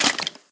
minecraft / sounds / mob / skeleton / step1.ogg
step1.ogg